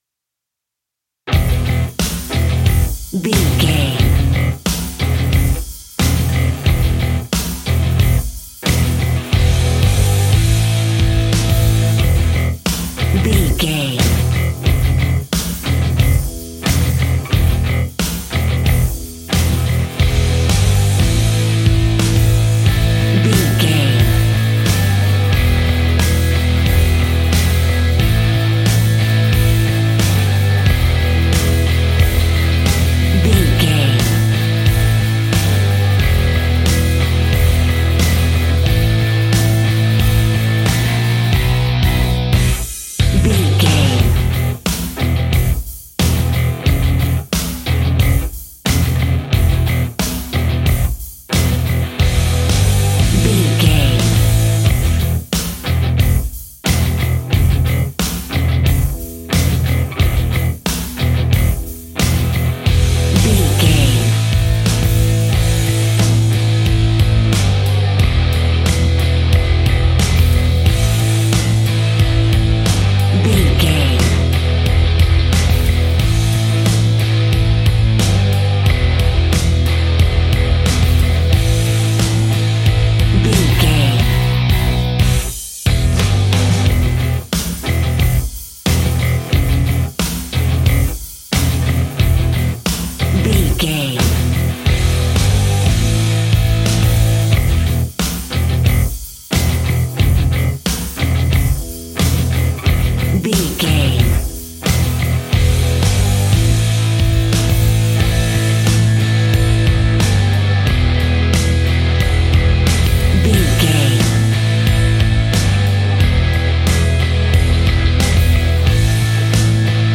Aeolian/Minor
Slow
hard rock
heavy metal
blues rock
distortion
instrumentals
Rock Bass
Rock Drums
heavy drums
distorted guitars
hammond organ